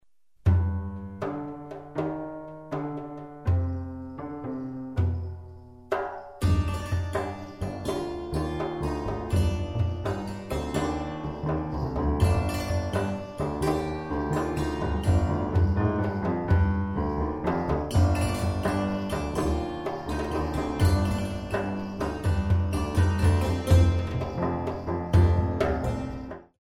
3/4  mm=126